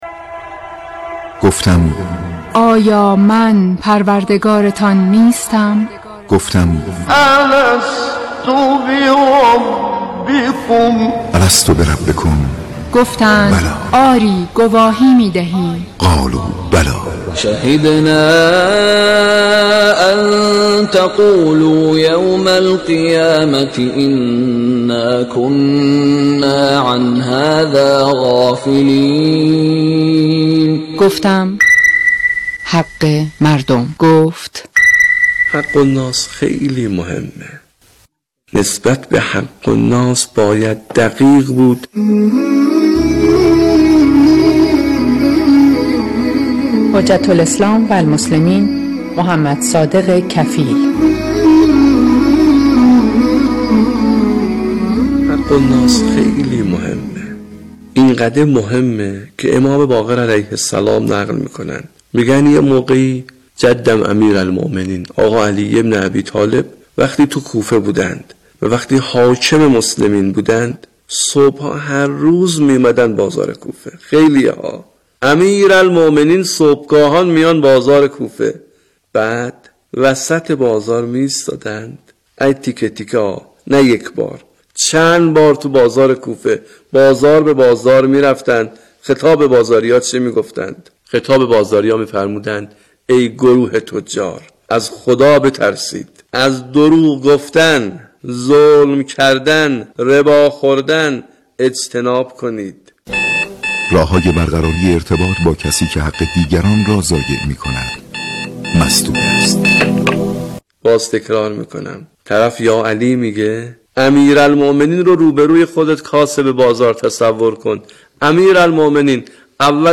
برنامه کوتاه «گفتم، گفت» با هدف تبیین مصادیق حق‌الناس از رادیو قرآن پخش می‌شود.
فرازهایی از شاهکارهای تلاوت را پخش می‌کند